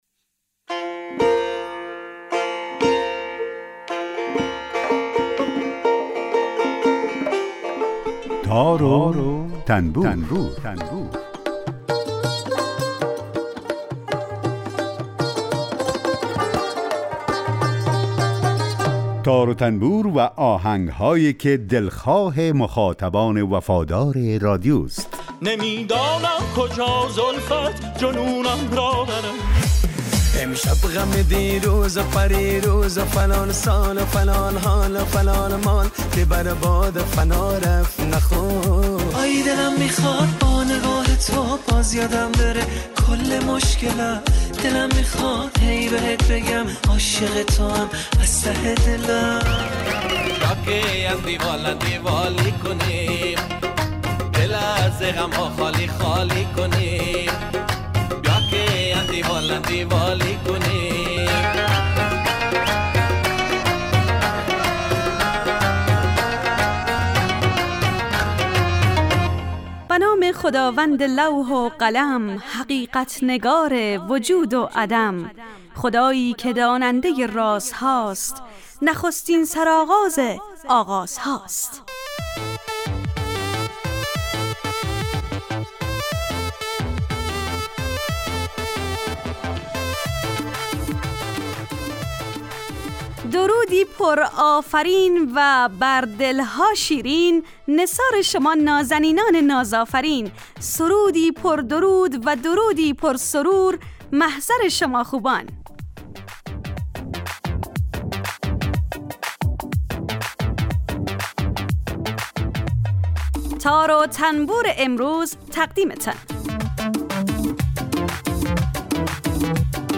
آهنگ های درخواستی از شما پخش آن از تار وتنبور